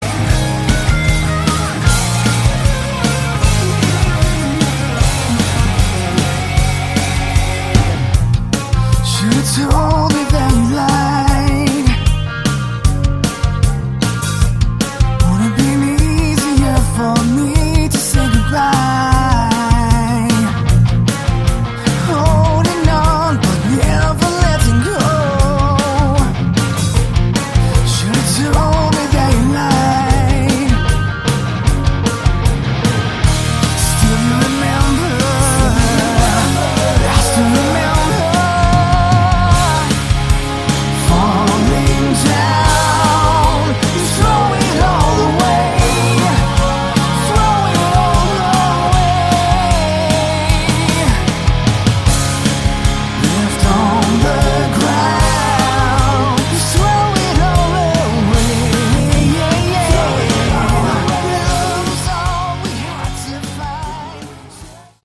Category: Melodic Rock
lead guitars, vocals
drums
keyboards, vocals
bass, vocals